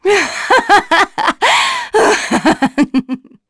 Shamilla-Vox_Happy4.wav